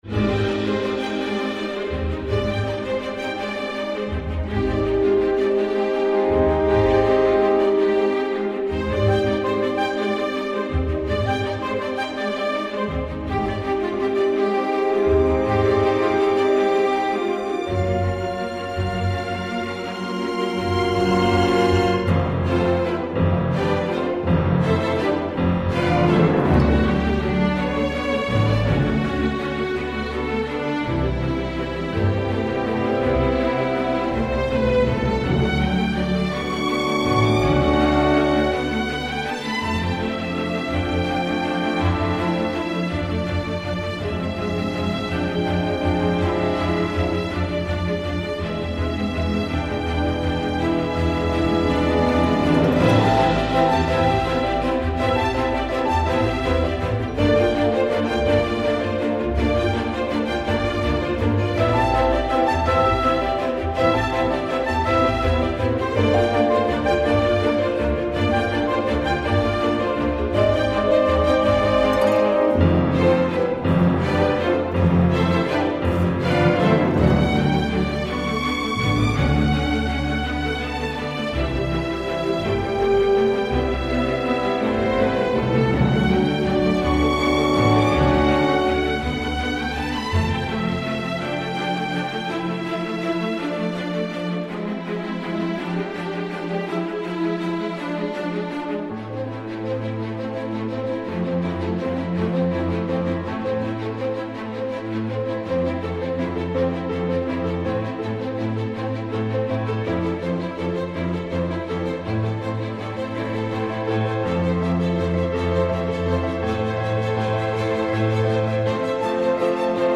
le jazz notamment